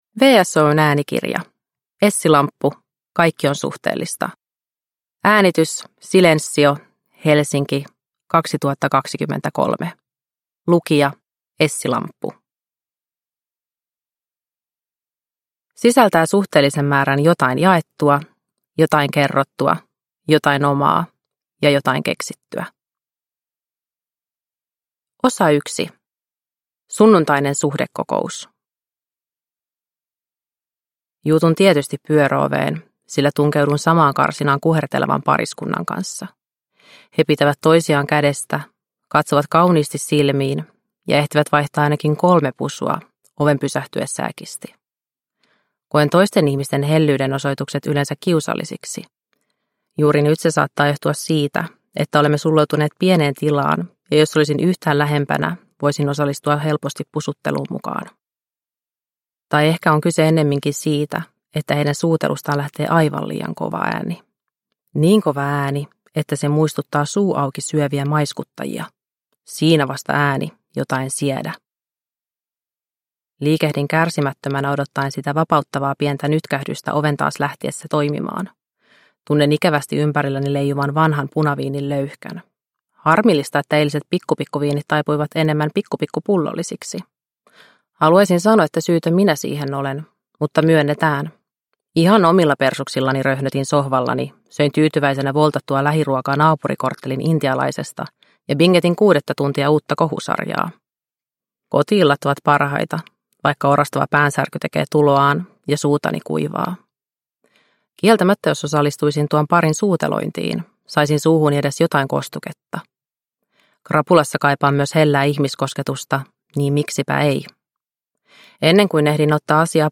Kaikki on suhteellista – Ljudbok – Laddas ner